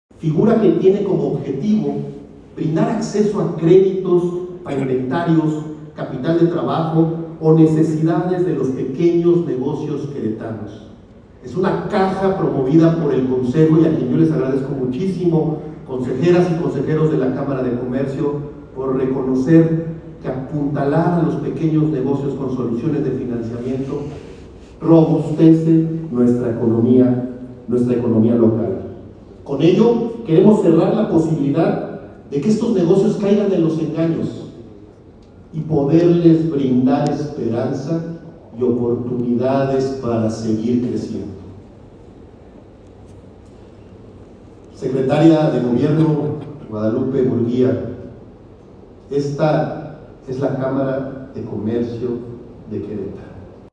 Durante su discurso en la ceremonia de rendición de protesta del nuevo Consejo Directivo